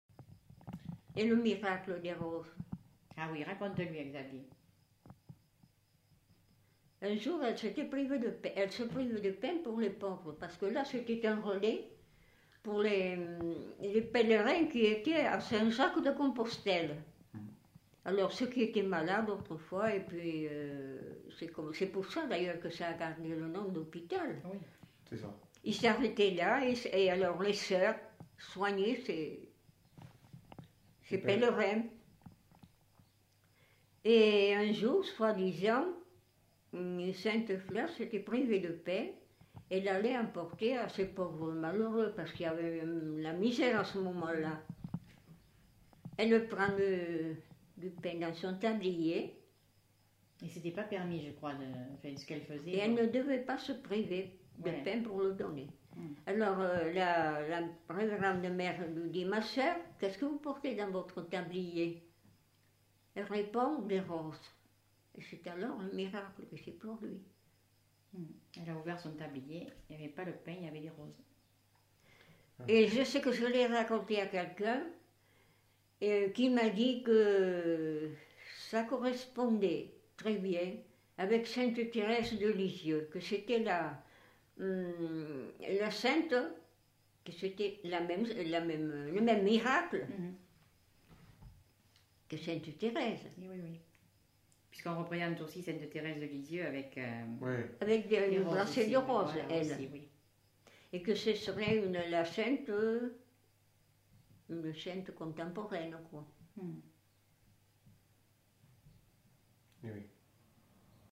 Aire culturelle : Quercy
Lieu : Gramat
Genre : conte-légende-récit
Effectif : 1
Type de voix : voix de femme
Production du son : parlé
Classification : récit légendaire